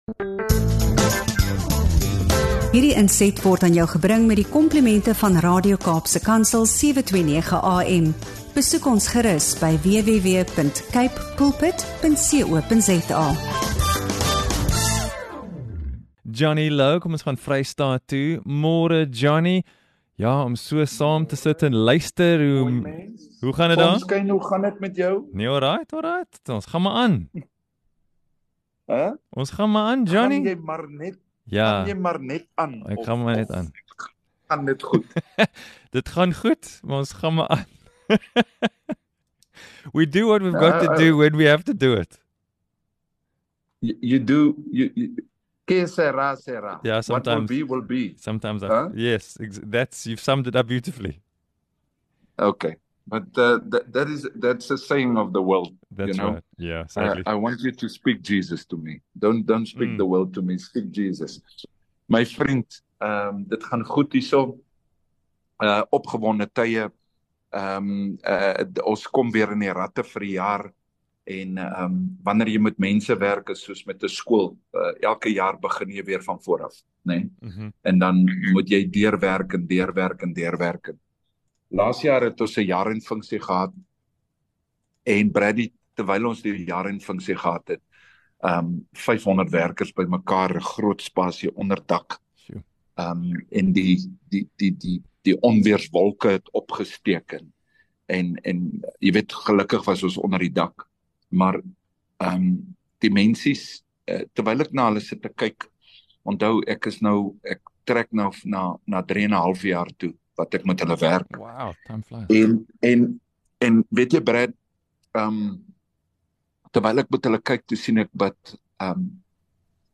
Luister na hierdie hartroerende gesprek oor geestelike groei, leierskap en die belangrikheid van 'n gemeenskap wat saamstaan in die gesig van storm.